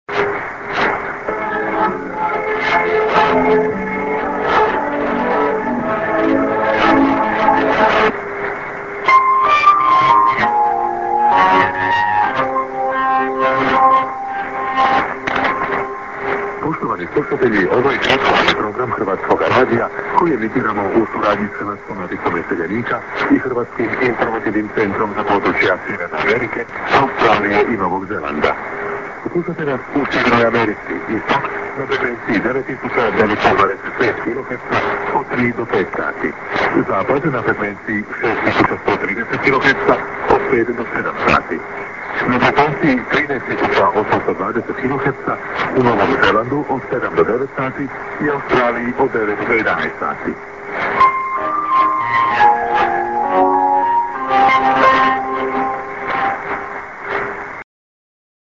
ST->SKJ(man)->ST Croatian R. via Julich Garmany